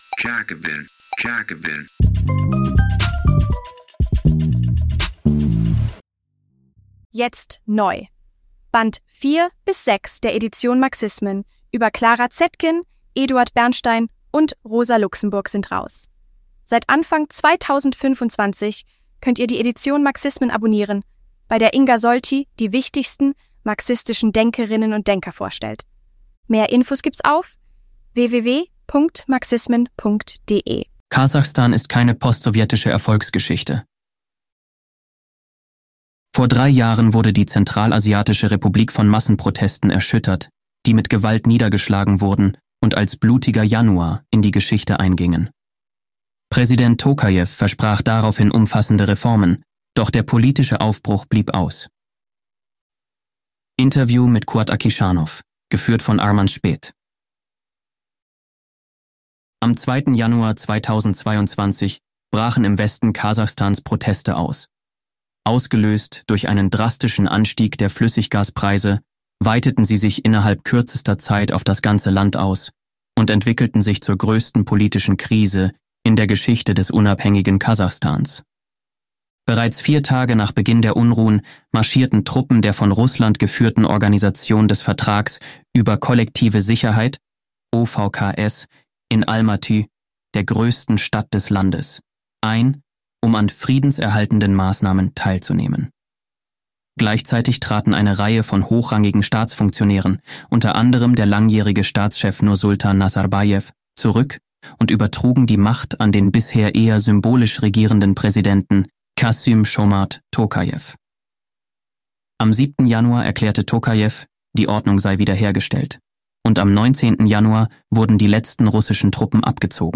Interview geführt vo...